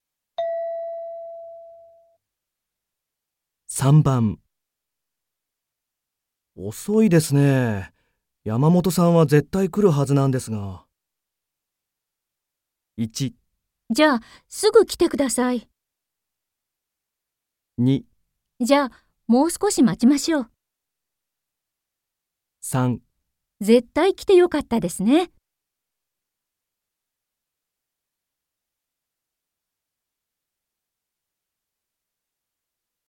問題4 ［聴解］